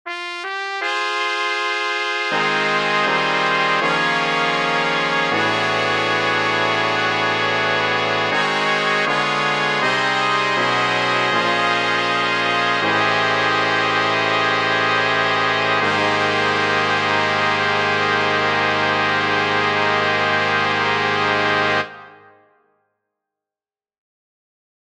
Key written in: A♭ Major
Type: Barbershop
All Parts mix: